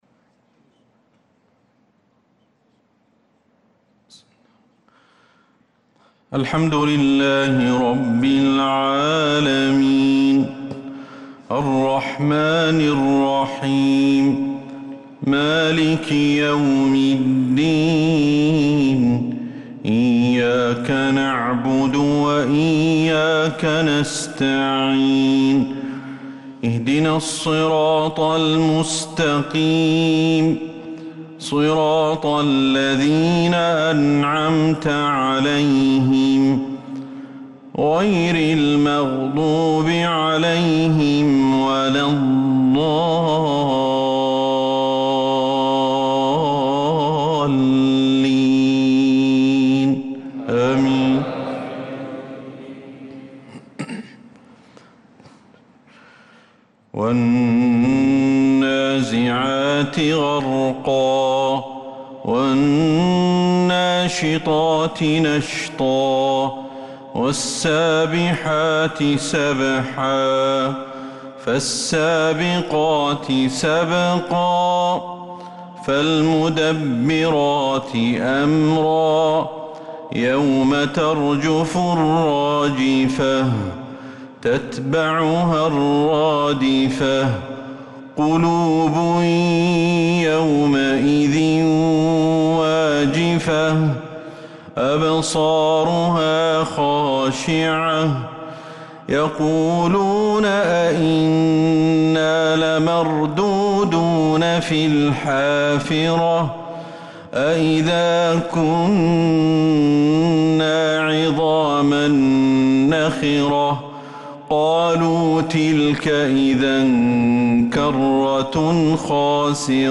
صلاة العشاء
تِلَاوَات الْحَرَمَيْن .